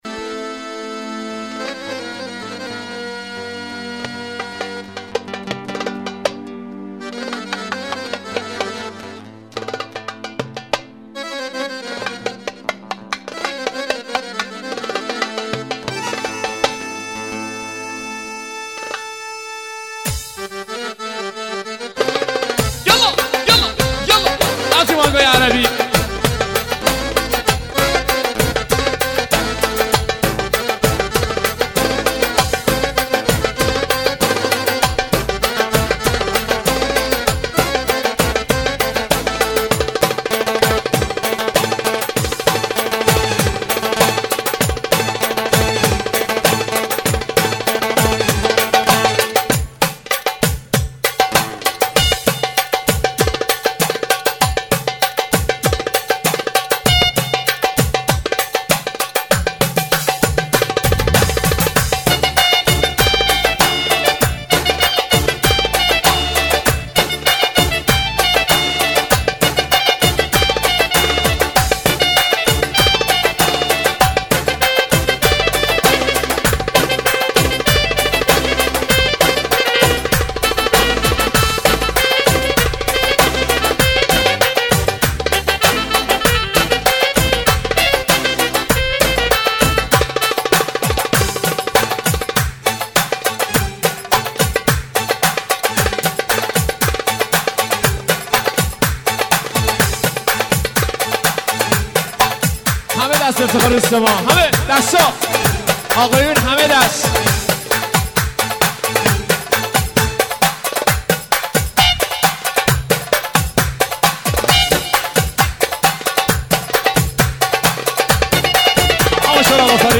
یه آهنگ خیلی شاد و پر انرژی بخاطر سال تحویل
یه آهنگ شاده بندری